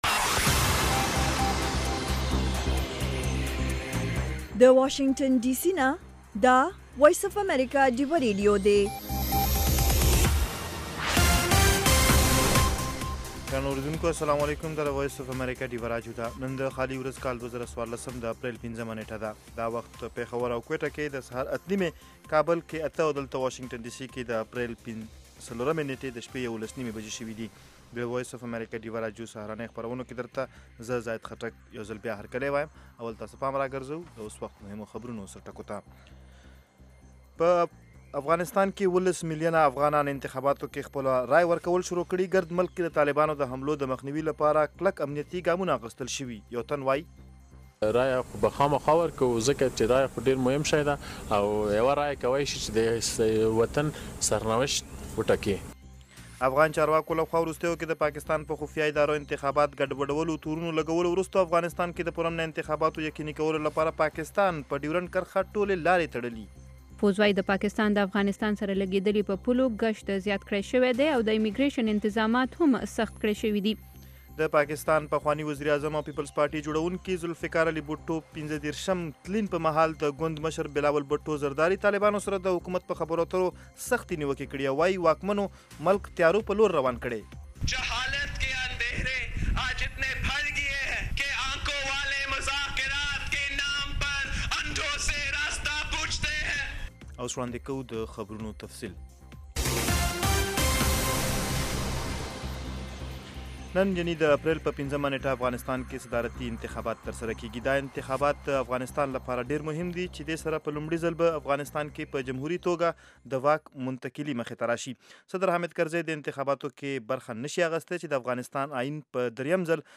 خبرونه - 0330
د وی او اې ډيوه راډيو سهرنې خبرونه چالان کړئ اؤ د ورځې دمهمو تازه خبرونو سرليکونه واورئ. په دغه خبرونو کې د نړيوالو، سيمه ايزو اؤمقامى خبرونو هغه مهم اړخونه چې سيمې اؤ پښتنې ټولنې پورې اړه لري شامل دي. دخبرونو په دې جامع وخت کې دسياسياتو، اقتصاد، هنر ، ټنګ ټکور، روغتيا، موسم اؤ لوبو په حقله ځانګړې ورځنۍ فيچرې شاملې دي.